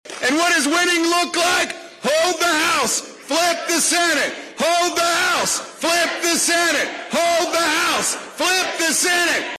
Virginians were voting in a legislative election and the polls suggested that Gov. Youngkin was going to achieve his goal, which he clearly stated at a campaign event in Manassas, Virginia Monday afternoon.